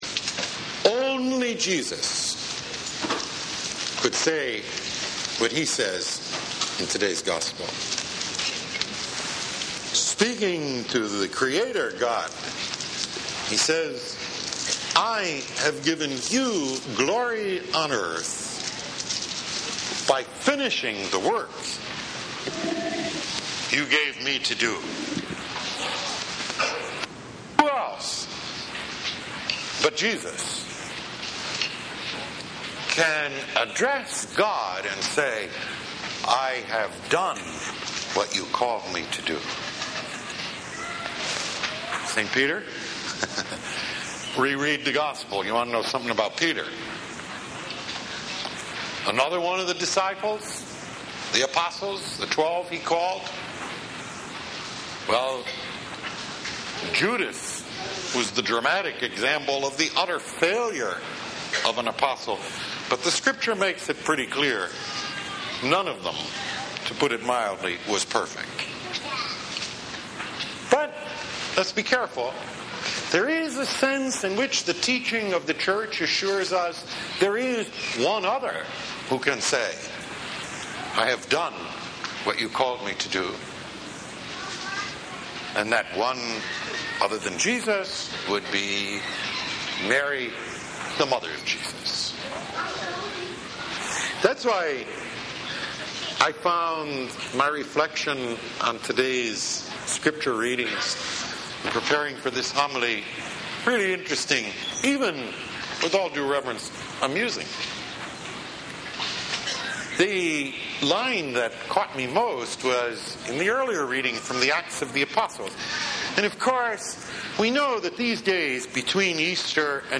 7th Sunday of Easter « Weekly Homilies